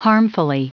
Prononciation du mot harmfully en anglais (fichier audio)
Prononciation du mot : harmfully